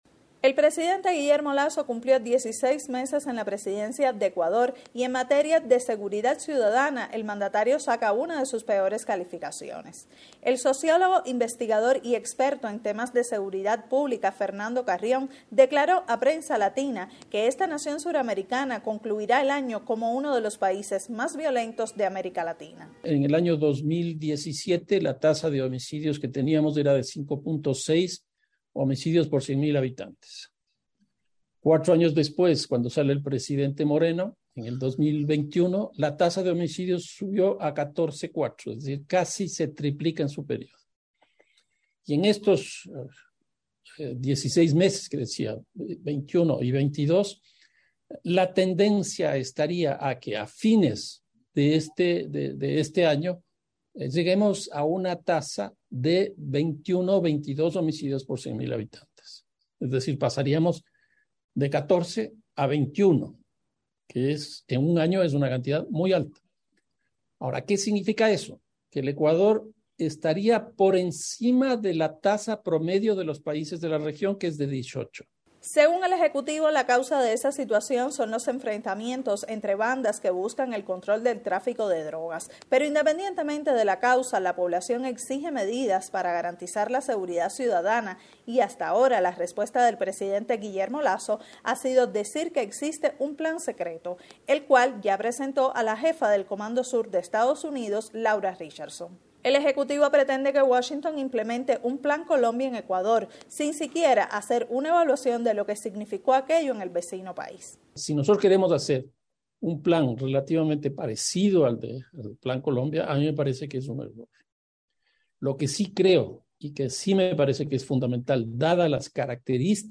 en entrevista exclusiva con Prensa Latina.